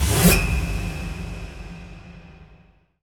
SI2 PIANO06L.wav